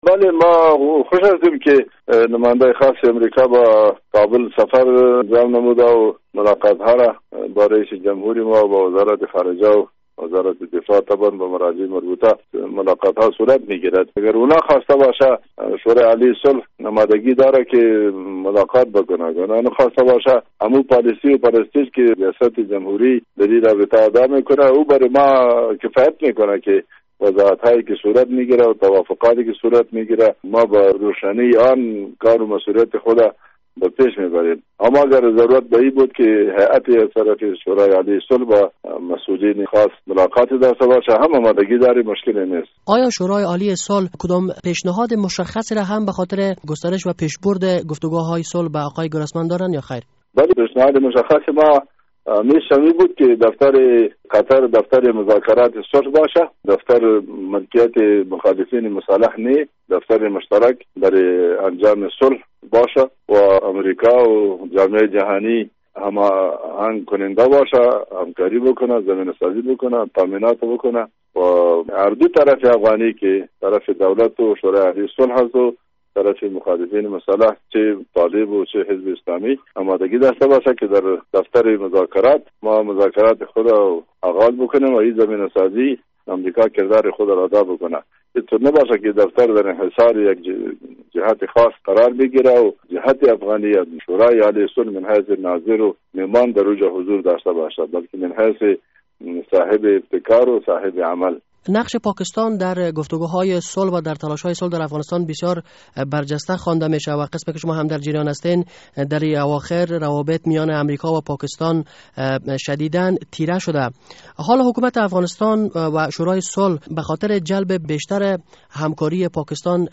مصاحبه: اثرات منفی روابط امریکا و پاکستان بر تلاش های صلح